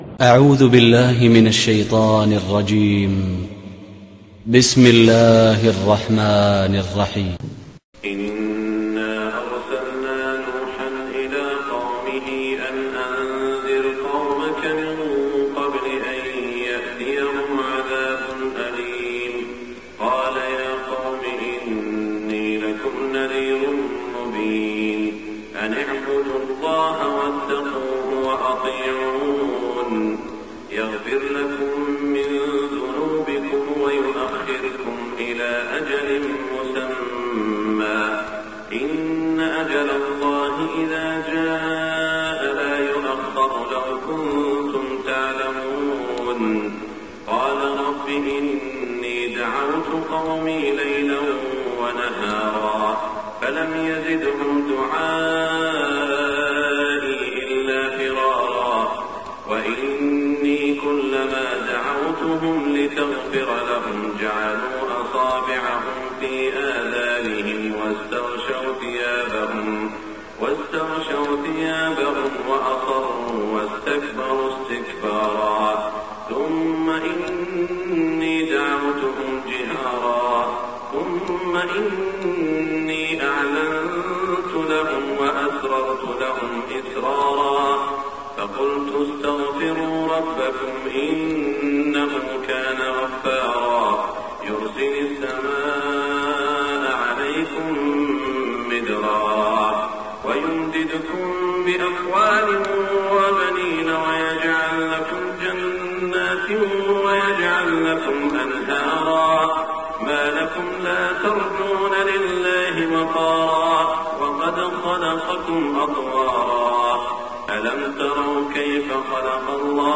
صلاة الفجر 1424 من سورة نوح > 1424 🕋 > الفروض - تلاوات الحرمين